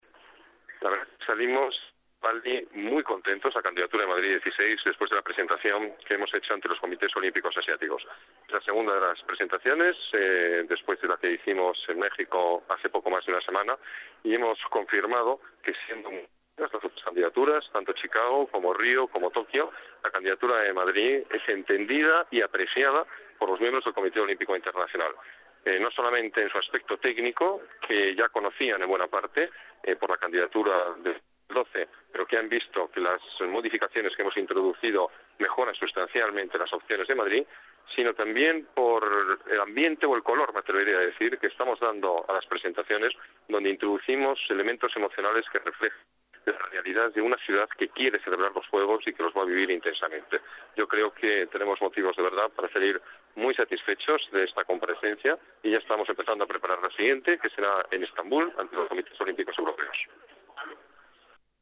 Nueva ventana:Declaraciones del alcalde en Bali